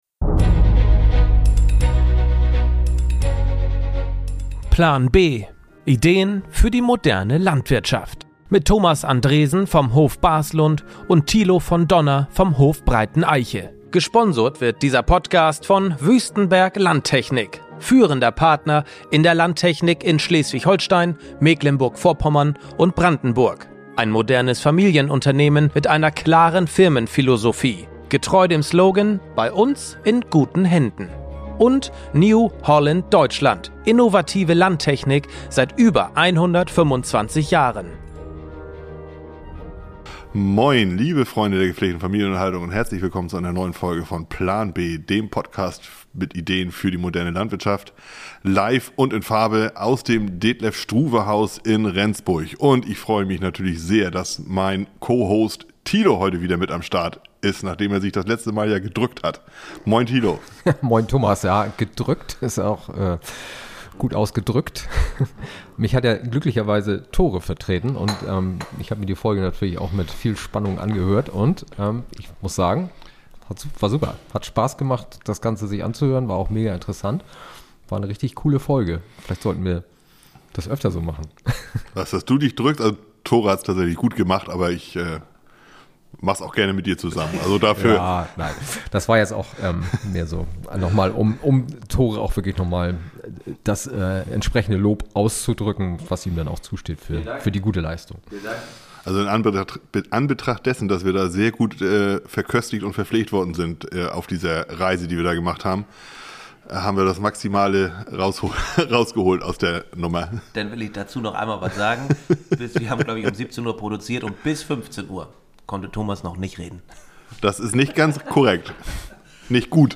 Kein Skript, kein doppelter Boden, nur pure Leidenschaft für Landwirtschaft und ehrliche Meinungen direkt vom Acker. Ob es um die Herausforderungen der Branche geht oder das Chaos der aktuellen Politik – hier wird nichts geschönt. Stattdessen gibt’s klare Worte, spontane Einsichten und das Gefühl, mit zwei alten Bekannten am Stammtisch zu sitzen.